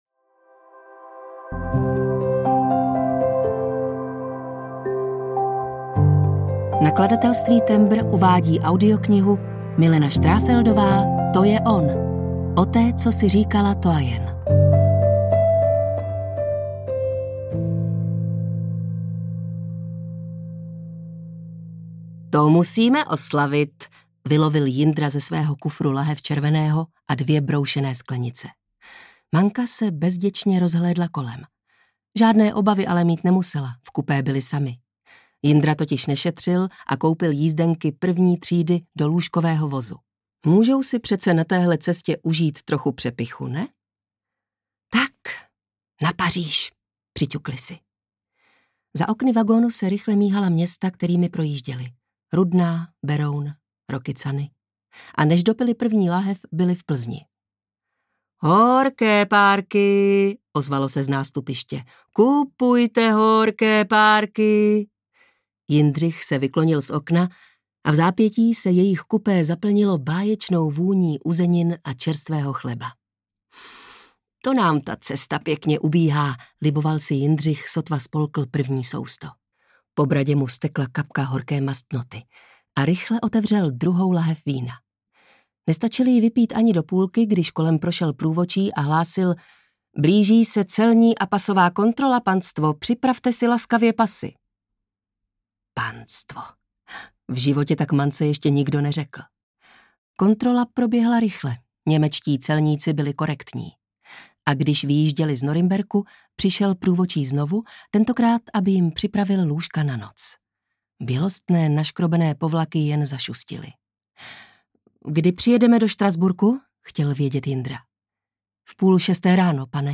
To je on! O té, co si říkala Toyen audiokniha
Ukázka z knihy